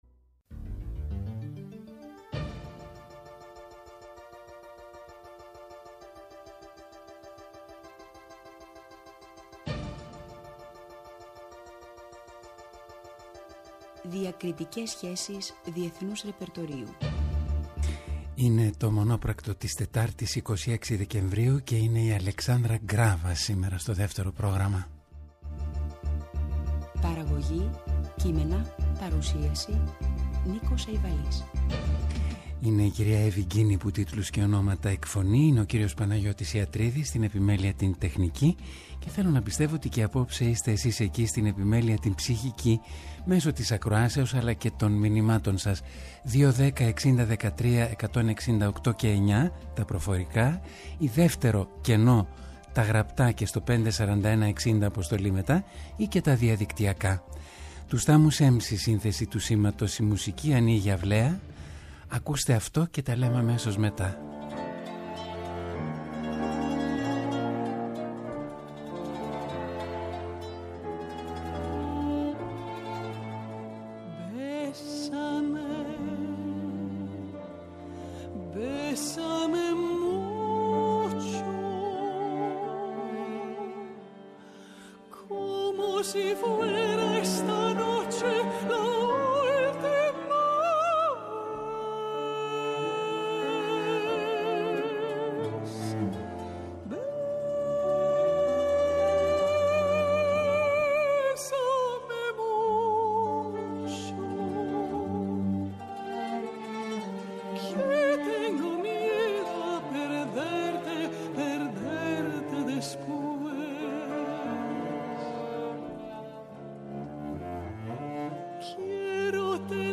Η διεθνούς φήμης μέτζο σοπράνο Αλεξάνδρα Γκράβας παρουσιάζει το νέο της άλμπουμ με τις ξεχωριστές ερμηνείες της σε τραγούδια διεθνούς ρεπερτορίου.
ΔΕΥΤΕΡΟ ΠΡΟΓΡΑΜΜΑ Μουσική Συνεντεύξεις